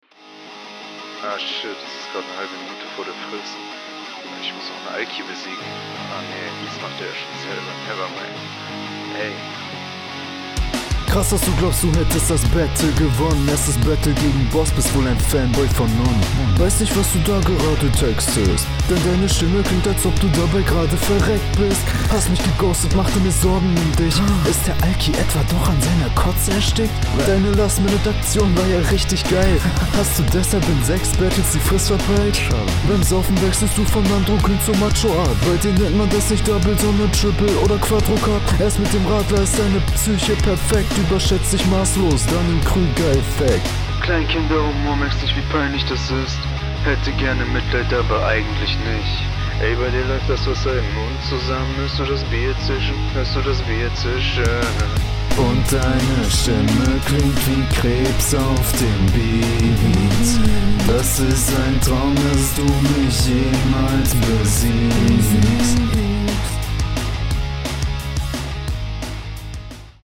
Technisch ist die Runde okay, aber du harmonierst leider überhaupt nicht mit dem Beat – …